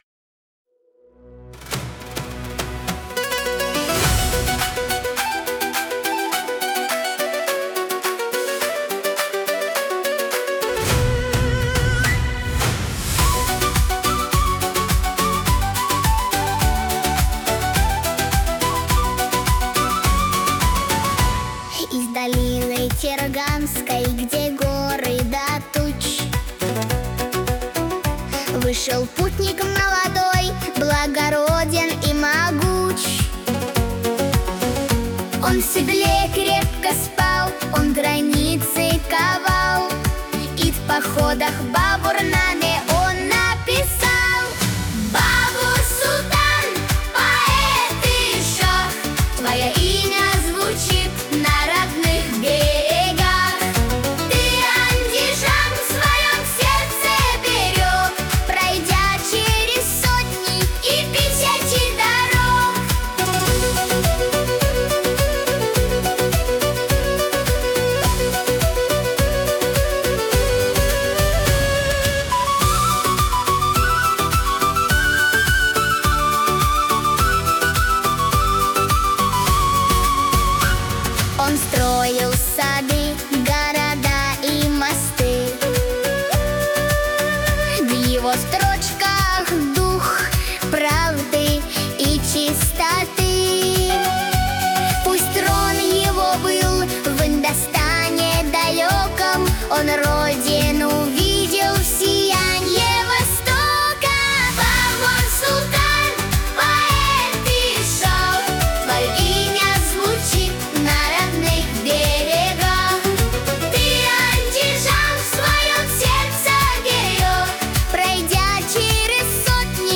• Жанр: Детские песни
Узбекские детские песни